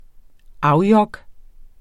Udtale [ -ˌjʌg ]